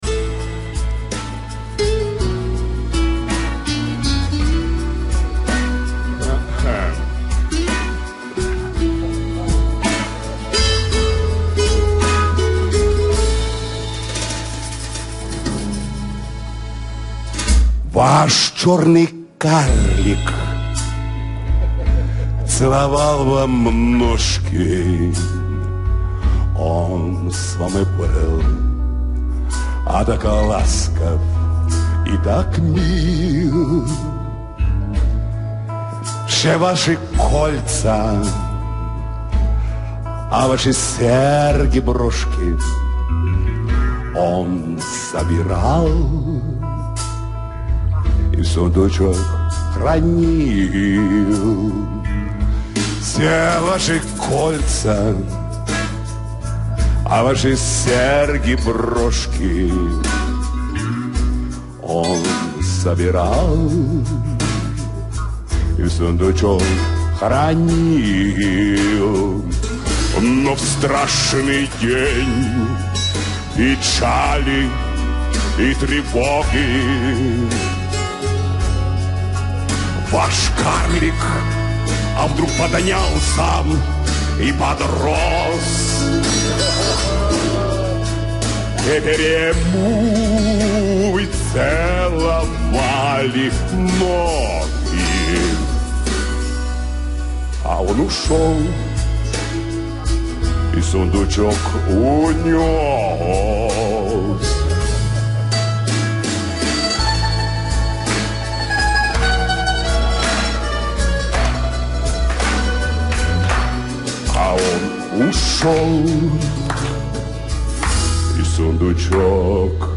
Так что, за качество не обессудьте